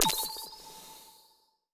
overlay-pop-out.wav